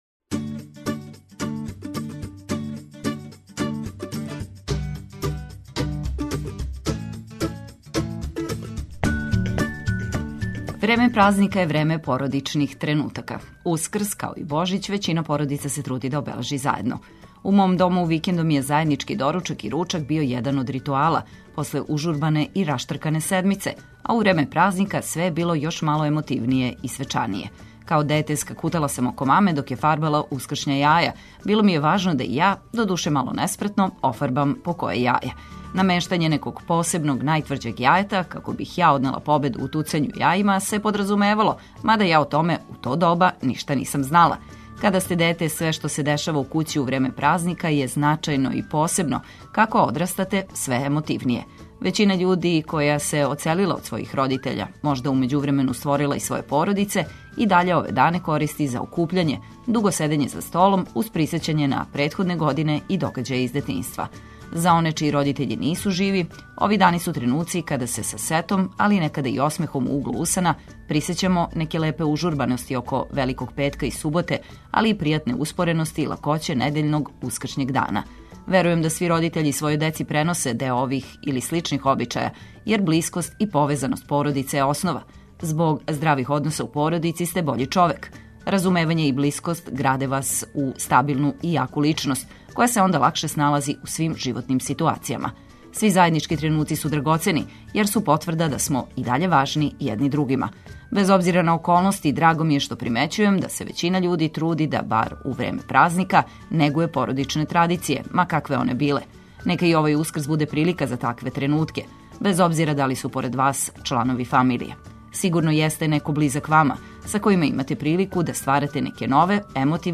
Ту су и ваше духовите мисли у рубрици „Добро јутро” уз много добре музике за расањивање.
Водитељка